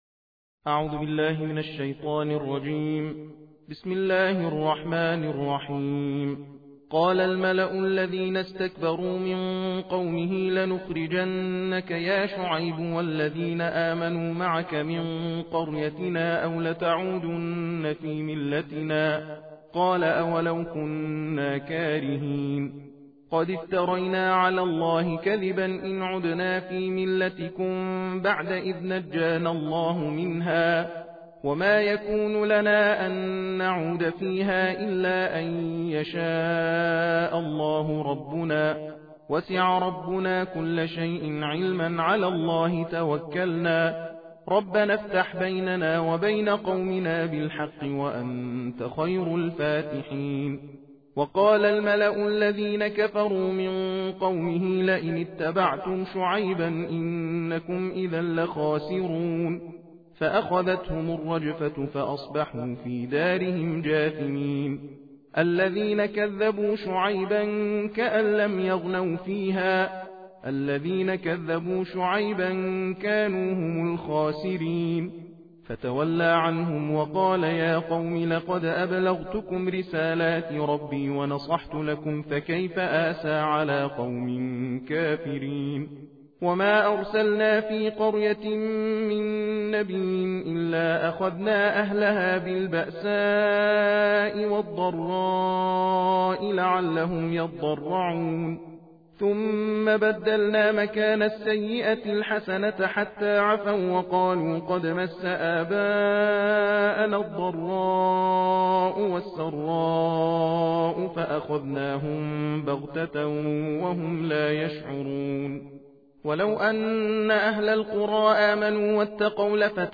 تلاوت جزء نهم قرآن‌کریم +متن و ترجمه
ترتیل جزء نهم قرآن کریم را در ادامه به همراه متن و ترجمه می توانید دریافت نمایید.